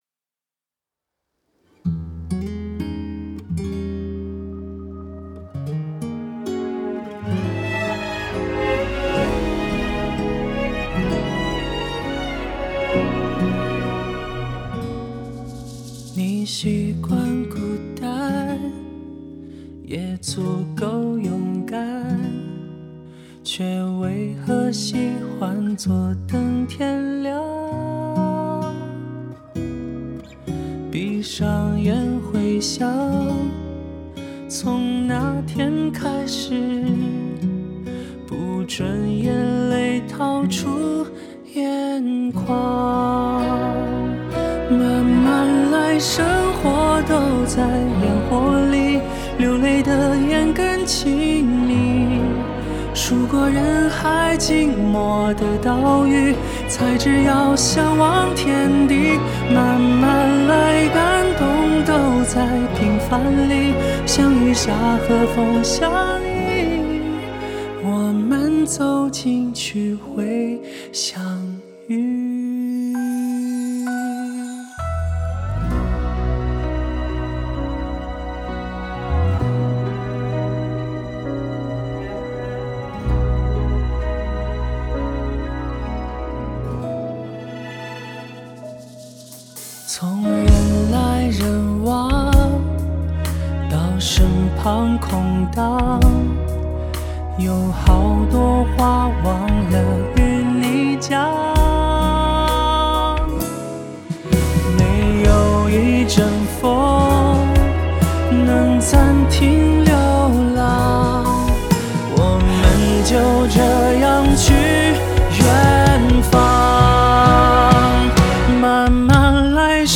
弦乐录音棚：2496 录音棚
人声录音棚：XXL Studio